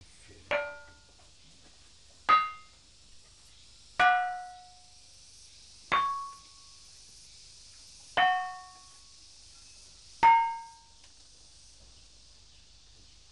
The six soundblocks of the talempong batu from Talang Anau
The sequence of the blocks according to their pitch is as described in the Text (from first to last: pitch No. 1 (deepest),6 (highest),2,5,3,4)